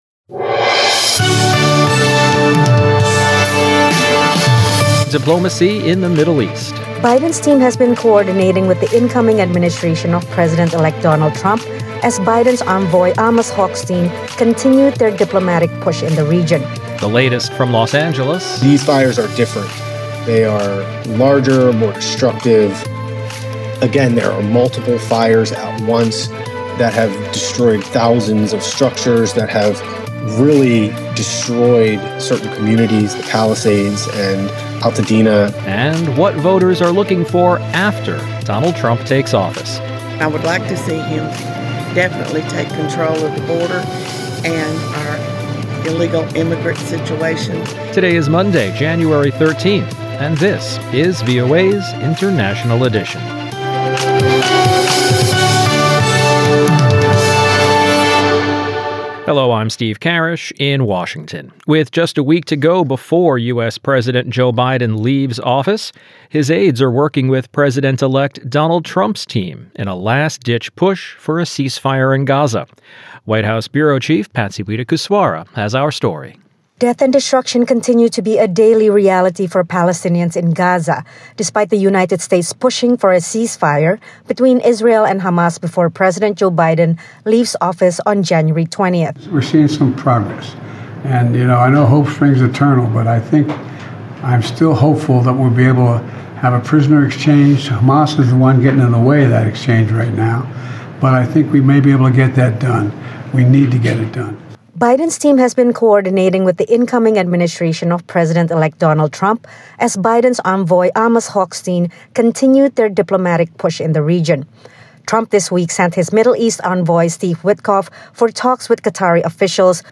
International Edition is VOA's premier global news podcast. Immerse yourself in the latest world events as we provide unparalleled insights through eye-witness accounts, correspondent reports, and expert analysis.